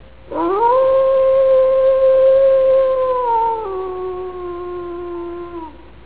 Listed below are samples that I have found of wolves howling.
wolf7.wav